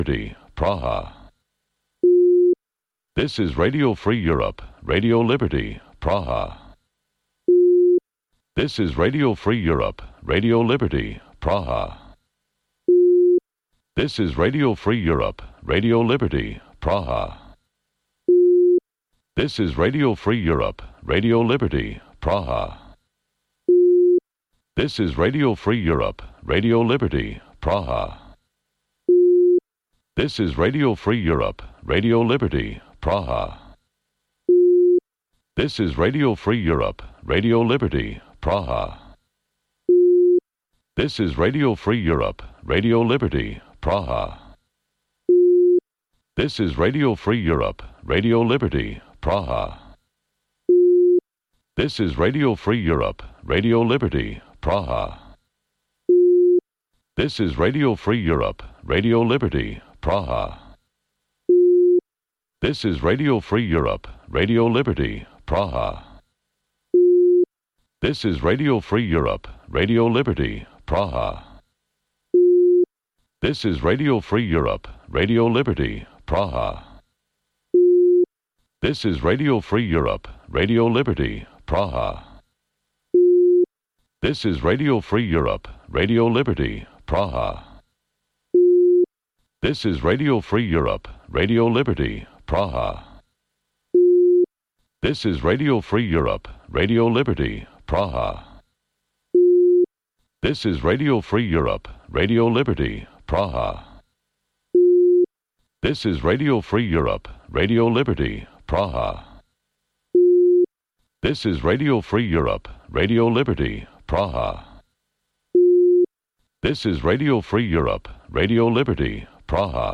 Утринска програма на Радио Слободна Европа од Студиото во Скопје. Во 15 минутната програма од понеделник до петок можете да слушате вести и прилози од земјата, регионот и светот. Во голем дел емисијата е посветена на локални настани, случувања и приказни од секојдневниот живот на граѓаните во Македонија.